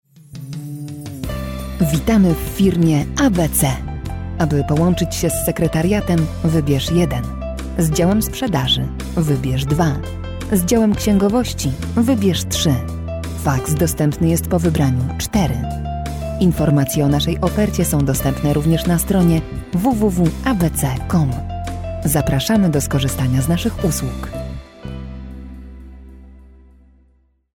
Female 20-30 lat
This voice will calm any listener.
Nagranie lektorskie